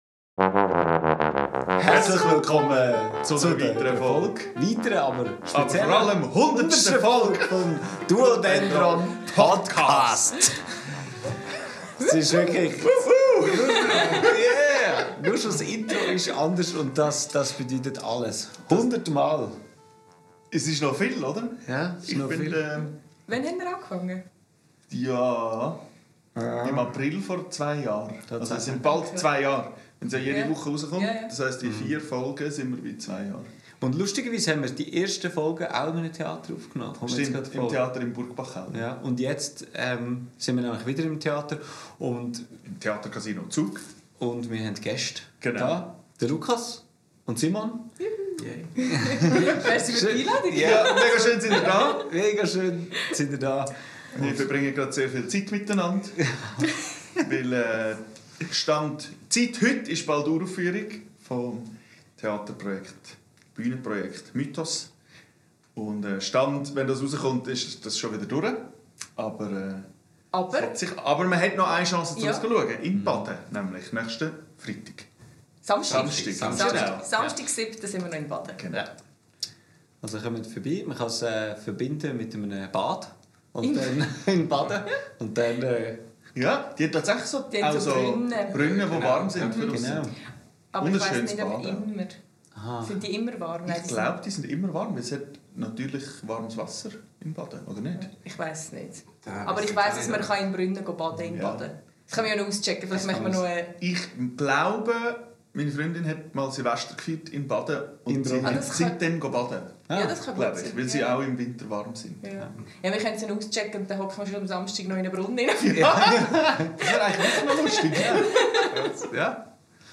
Wir haben zusammen etwas über die Zahl 100 philosophiert und was alles passieren könnte, wenn man etwas 100 Mal macht. Aber natürlich präsentieren wir auch einen Teil der Musik von "Mythos". Aufgenommen am 24.02.2026 in der Garderobe des Theater Casino Zug.